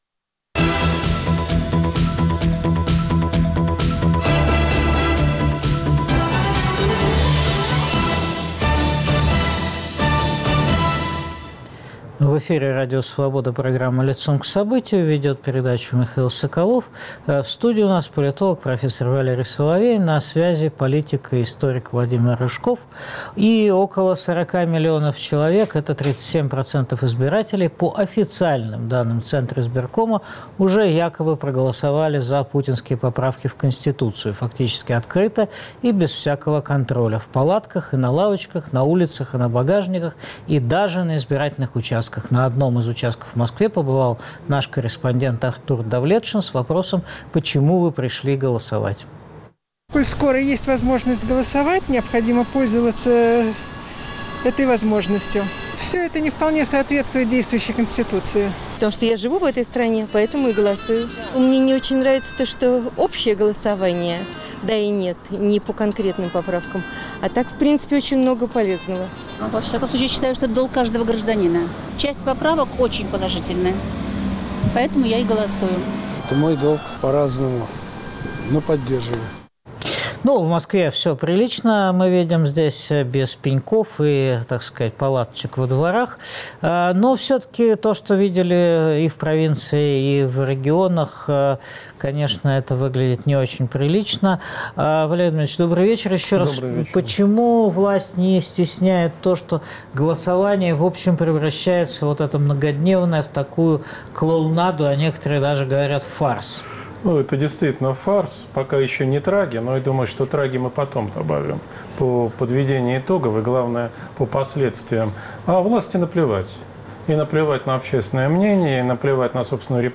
Что обещает Владимир Путин? Обращения президента и планы его администрации обсуждают политик Владимир Рыжков, политолог Валерий Соловей.